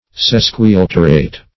\Ses`qui*al"ter*ate\, a. [L. sesquialter once and a half;